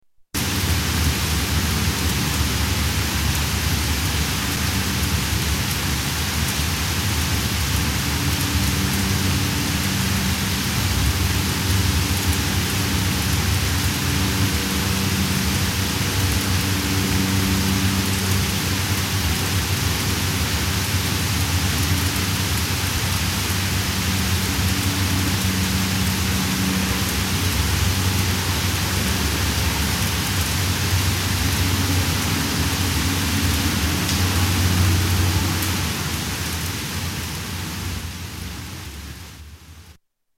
Rain in courtyard